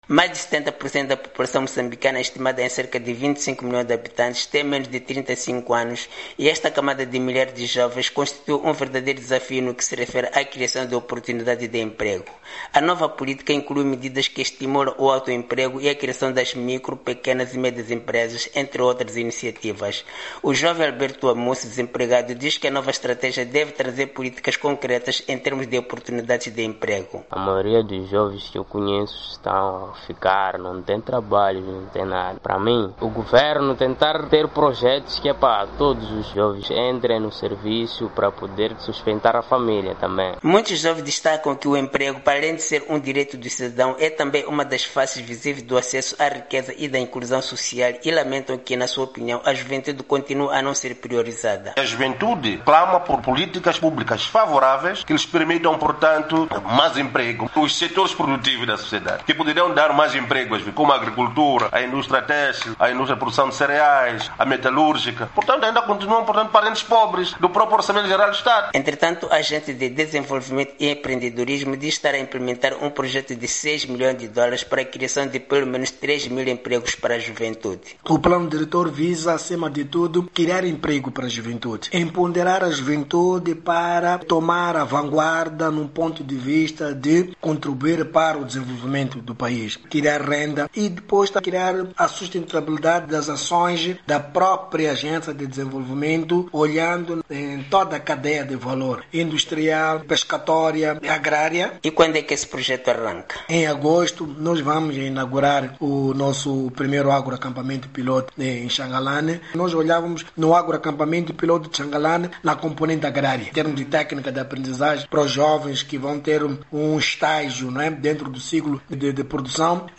Jovens moçambicanos, entrevistados pela VOA, dizem esperar que a nova política de emprego aprovada esta semana pelo governo se traduza em oportunidades de trabalho para a juventude, que tem sido a mais afectada pelo desemprego no país.